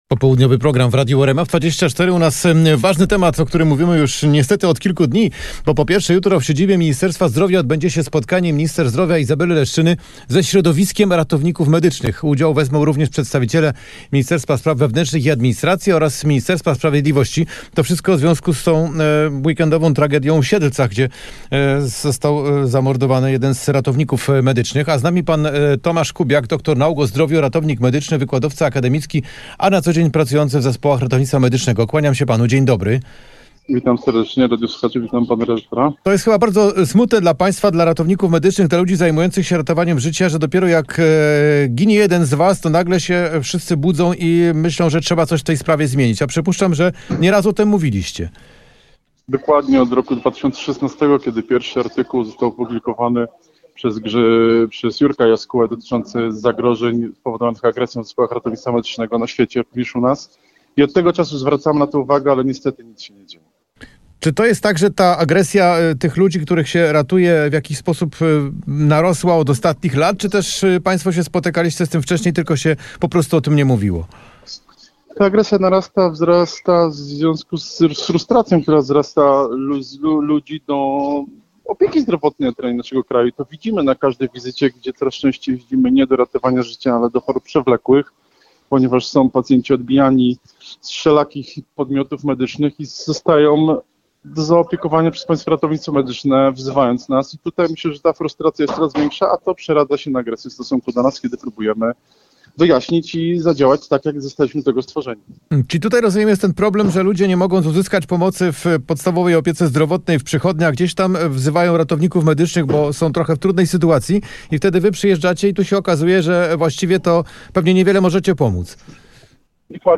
18:00 Fakty i Popołudniowa rozmowa w RMF FM - 28.01.2025